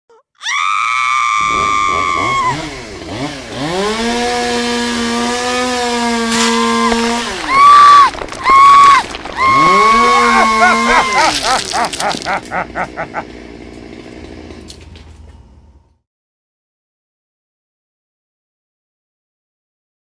Chainsaw kill
Category: Sound FX   Right: Personal